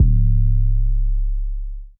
808 - Sizzle.wav